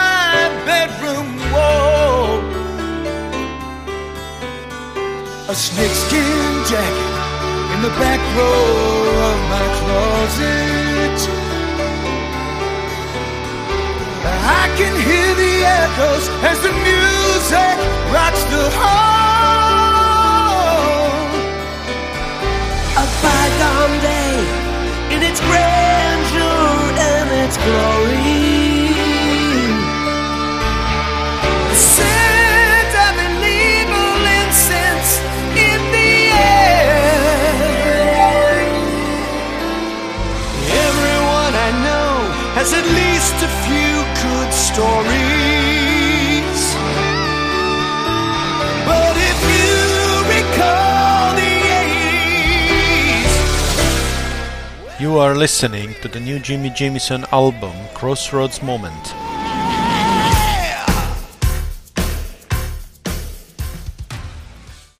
Category: AOR
lead and backing vocals
drums
guitar, keyboards, backing vocals